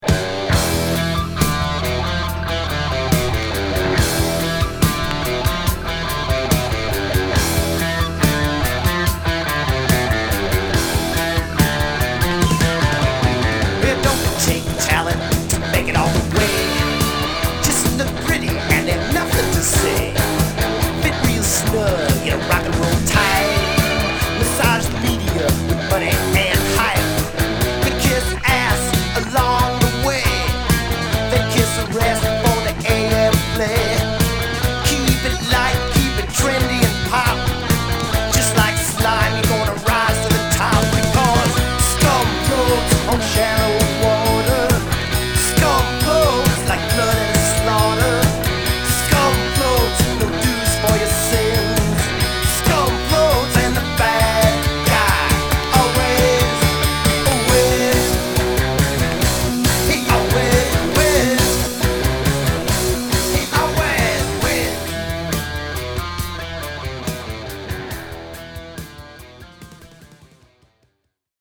・ ROCK LP